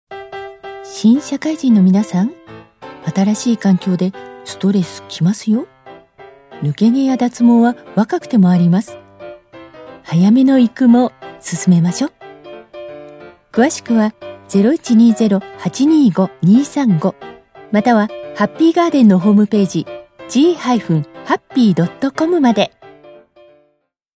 音声サンプル
落ち着いた喋りと優しく穏やかな声で、安定感のあるメッセージをお届けします。